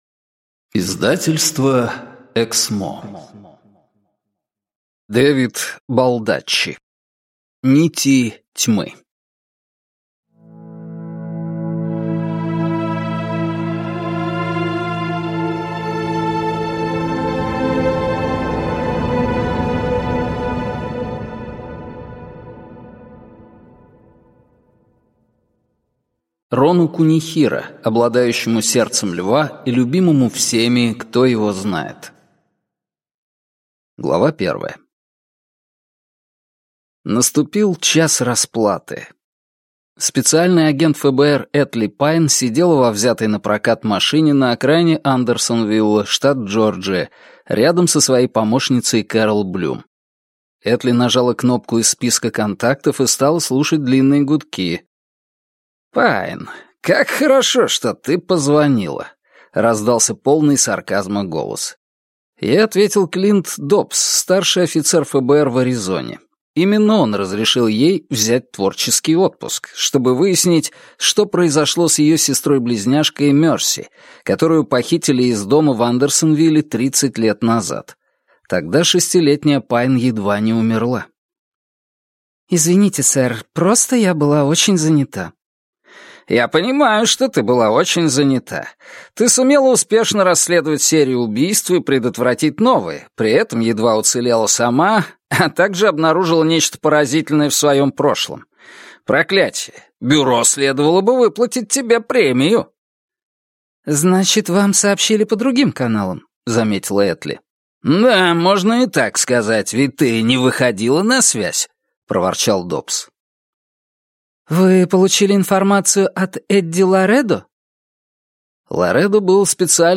Аудиокнига Нити тьмы | Библиотека аудиокниг